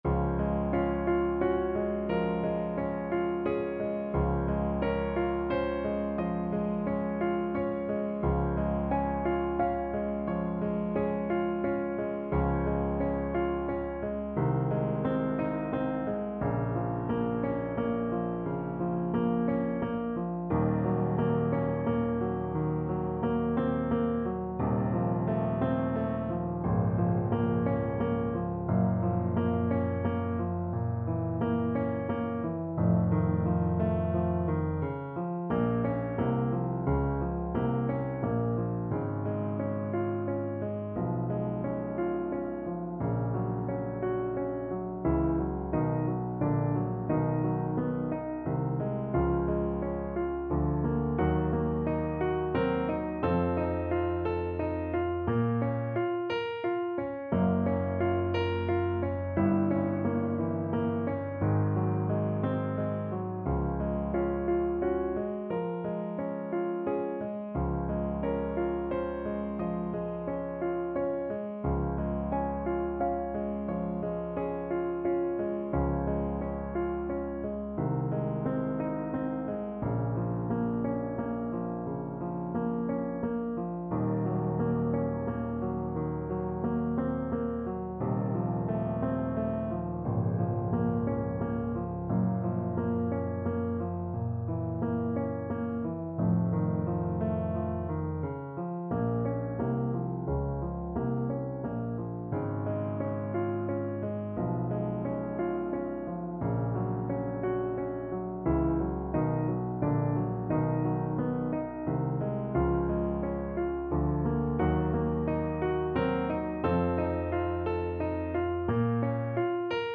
~ = 88 Andante
Classical (View more Classical Saxophone Music)